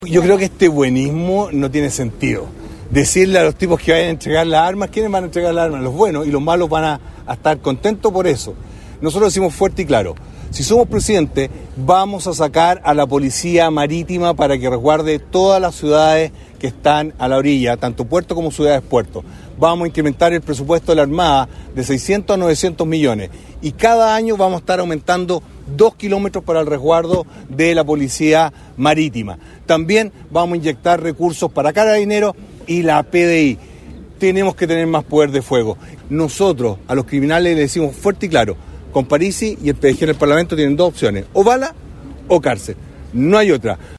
Desde la Plaza Independencia, en el centro de Concepción, y rodeado por alrededor de un centenar de adherentes, el postulante del Partido de la Gente (PDG) presentó algunas de sus propuestas, entre las que figura la baja de sueldos en la administración del estado, la devolución del IVA en los medicamentos y el término de las devoluciones de gasto electoral.